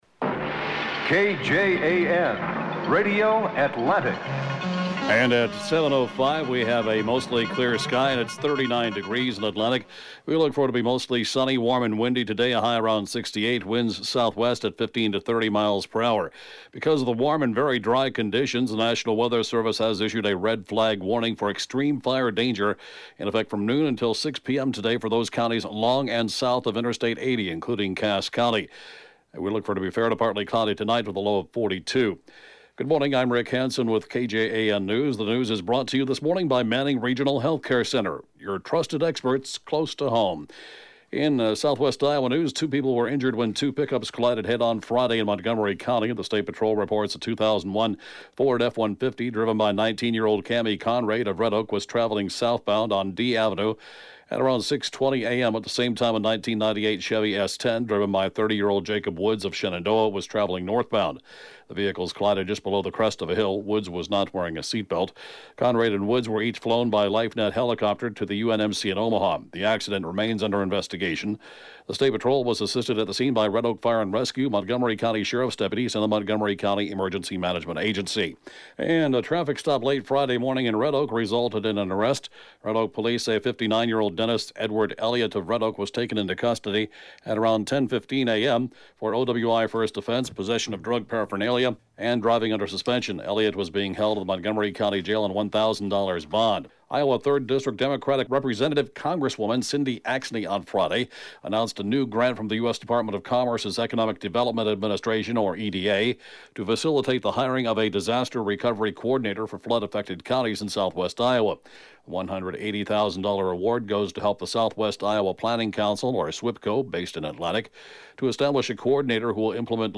(Podcast) KJAN Morning News & Funeral report, 3/7/20